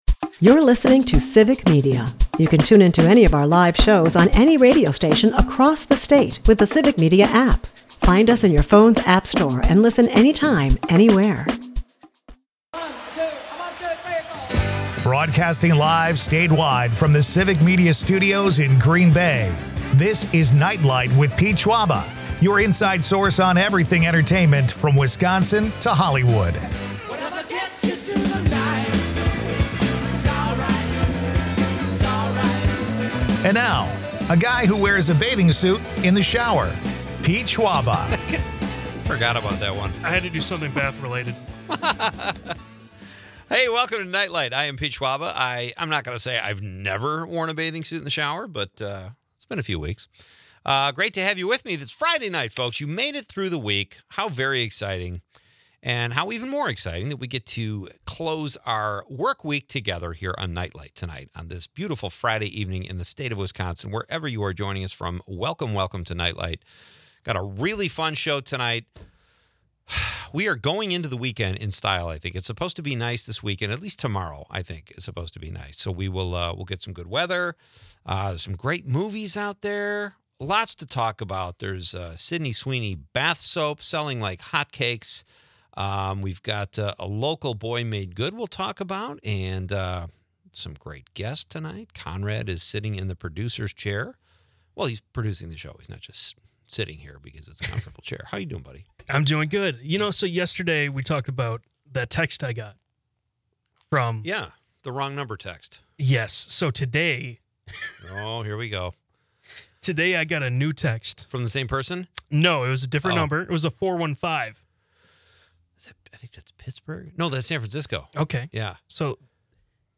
is a part of the Civic Media radio network and airs Monday through Friday from 6-8 pm across Wisconsin.